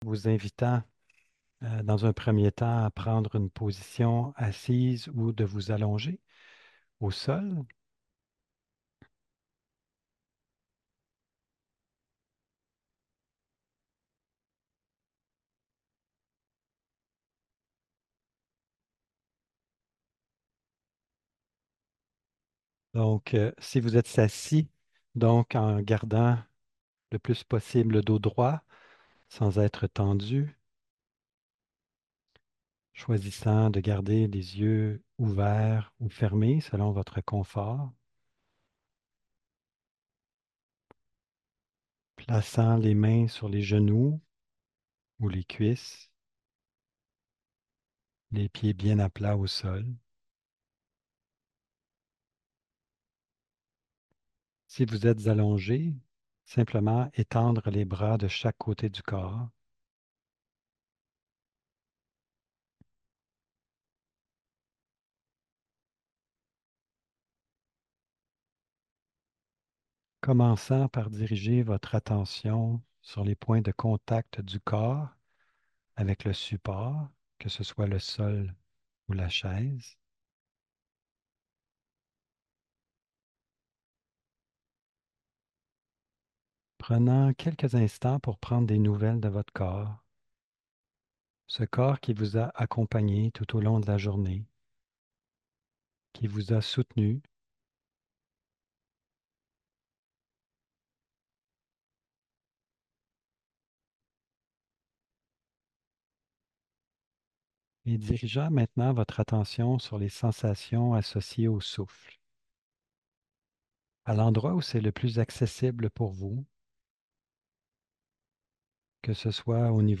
Une nouvelle méditation assise
S4-Meditation-assise.mp3